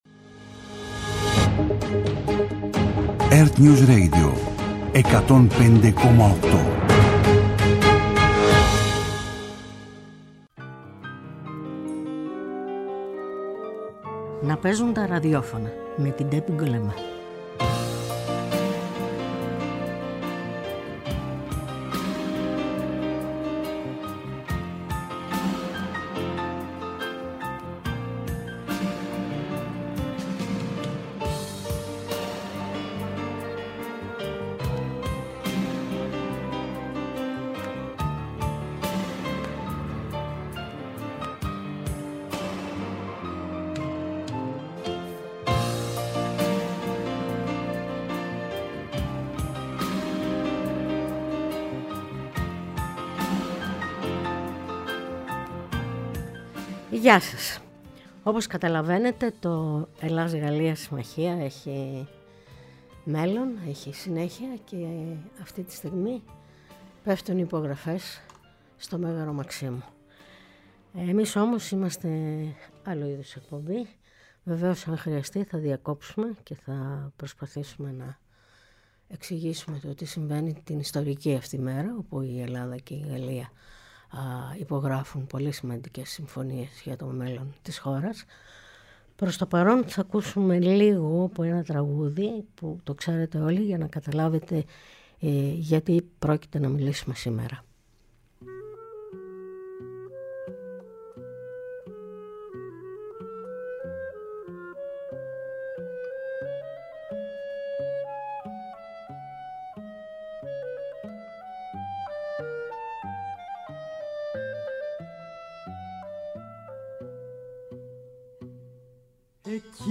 Σήμερα, λόγω της επίσκεψης του Γάλλου Προέδρου Εμανουέλ Μακρόν, η εκπομπή είναι προσαρμοσμένη στην επικαιρότητα και μεταδίδει απ’ ευθείας τις δηλώσεις του Γάλλου Προέδρου και του Πρωθυπουργού Κυριάκου Μητσοτάκη.